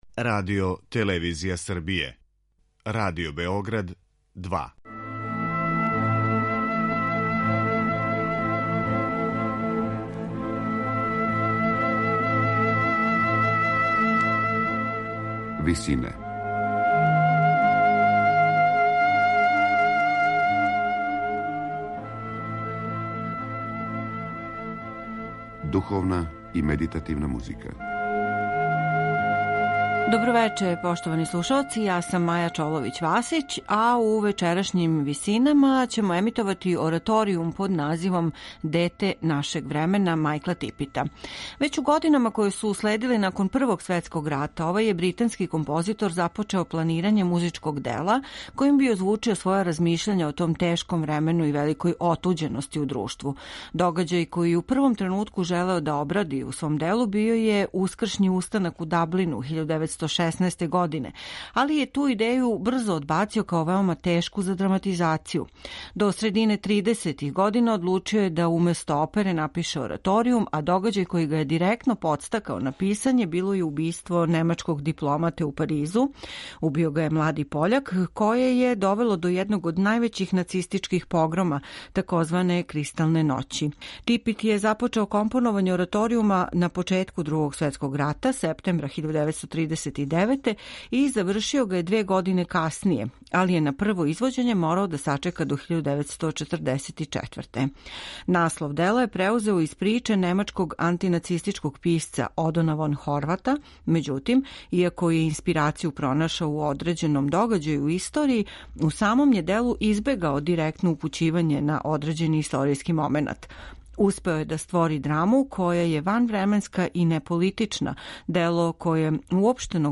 ораторијума